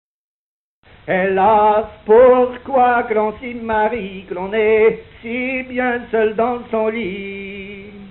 Genre énumérative
Pièce musicale inédite